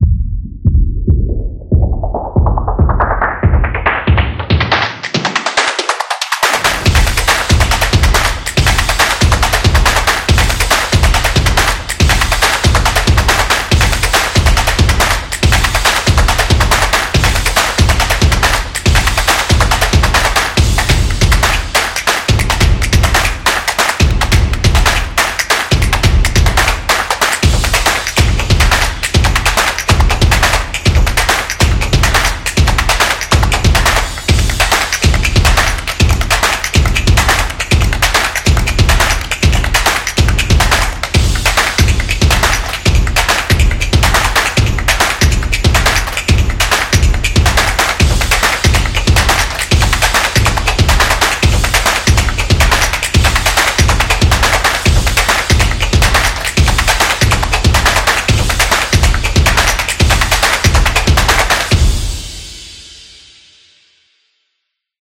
Percussion